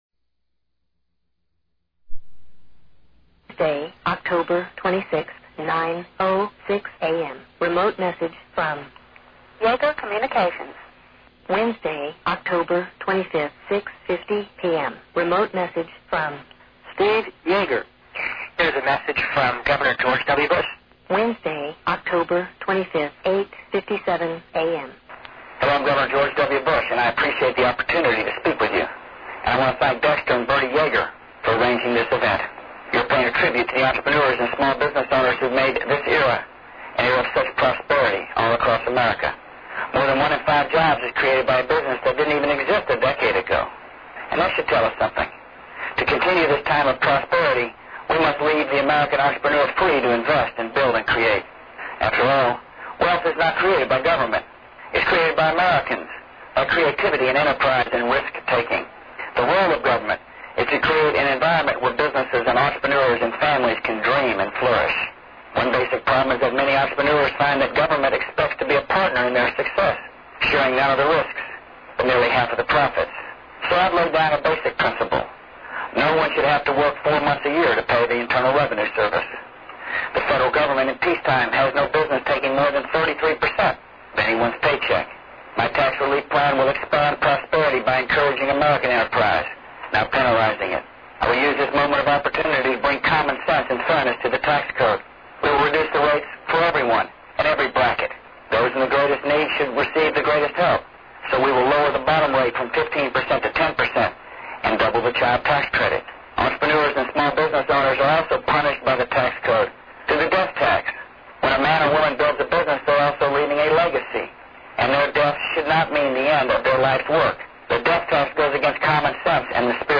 Click On MP3 Icon To Hear George W. Bush Rally The Amway Troops!